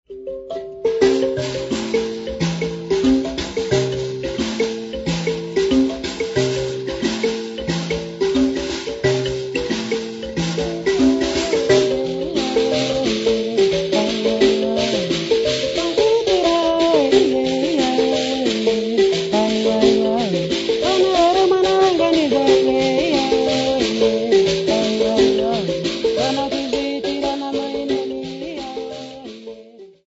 Self-delectative song
Indigenous folk music
Mbira dza waNdau
Complaint song
Chipungabera district
Mozambique
An indigenous self-delectative song played on mbira dza waNdau
Hugh Tracey (Recorded by)